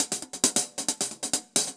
UHH_AcoustiHatC_135-02.wav